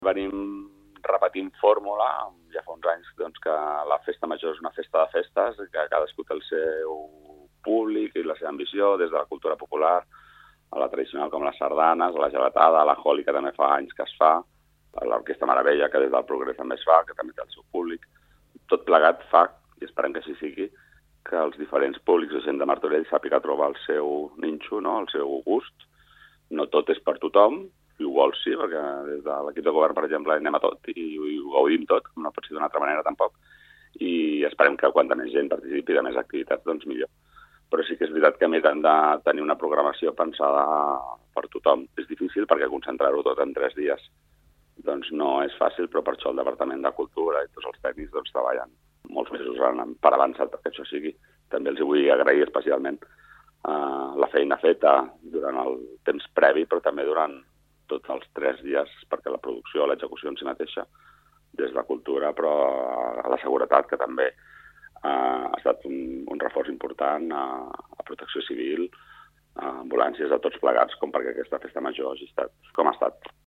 Sergi Corral, regidor de Cultura de l'Ajuntament de Martorell